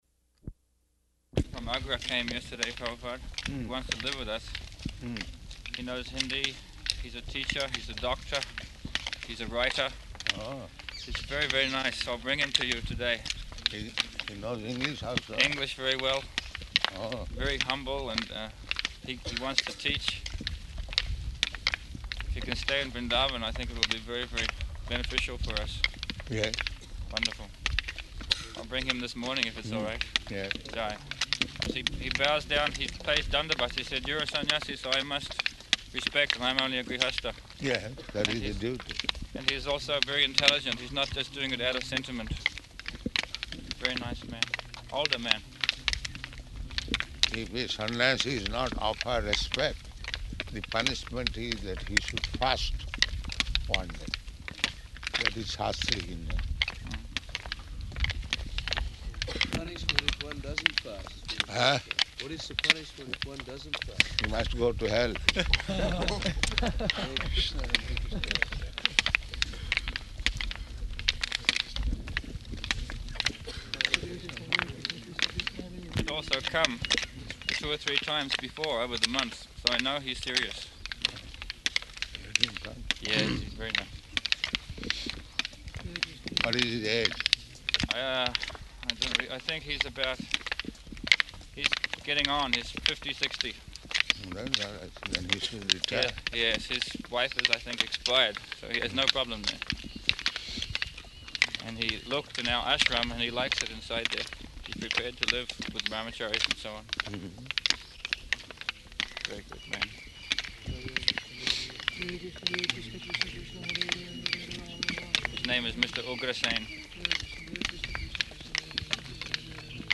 -- Type: Walk Dated: December 7th 1975 Location: Vṛndāvana Audio file